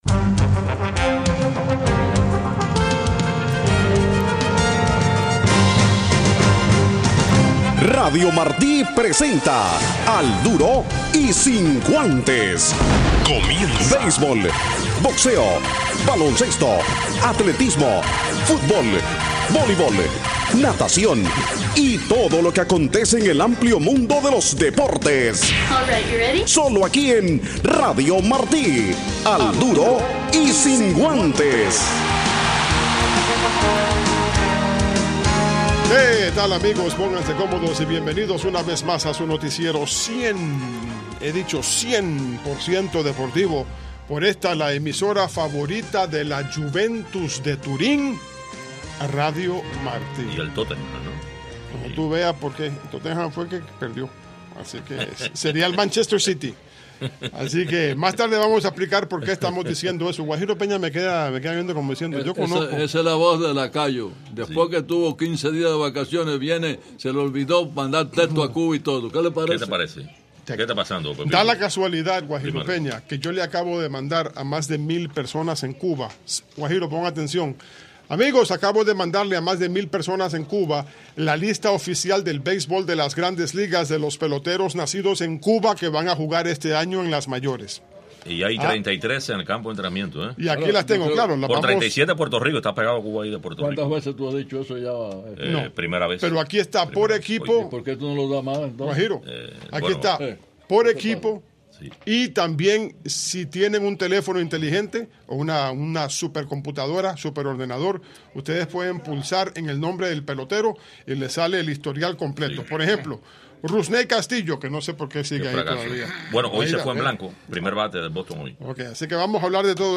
reportaje especial